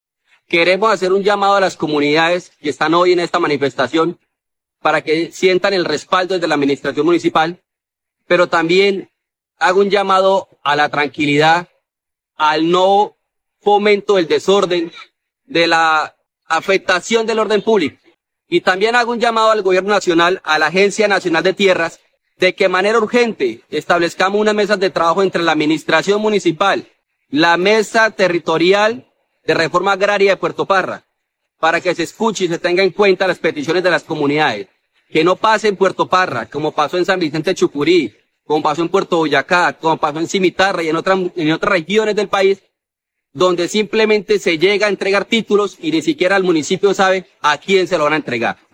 Diectsen Téllez Cadavid, alcalde de Puerto Parra